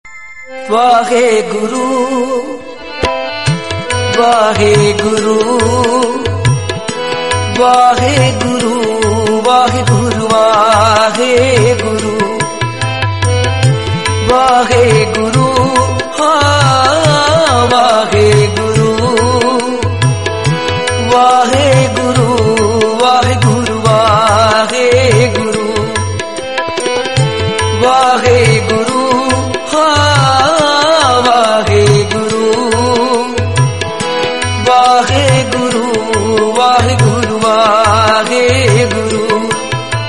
is a meditative piece with deep spiritual undertones
A meditative melody with healing energy
Religious